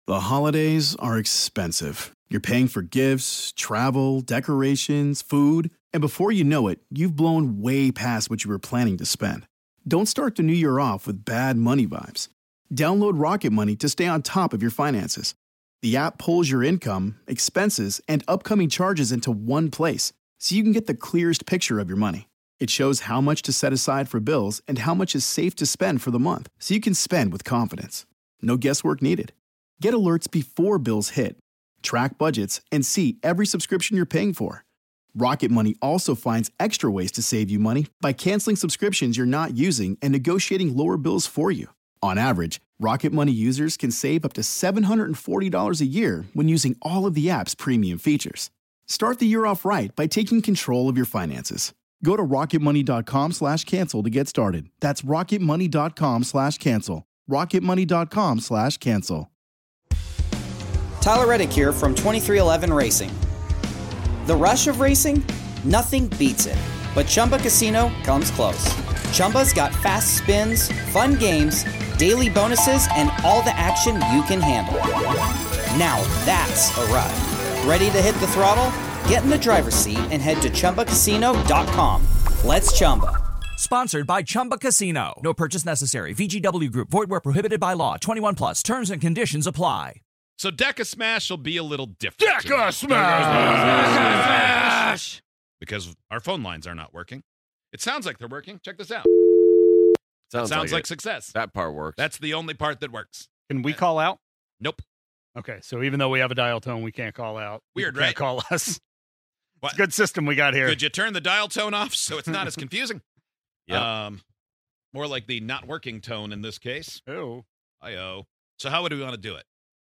put together a list of ten songs, smashed them all together, and played them at the same time. Can you guess any of the 10 songs from DECASMASH?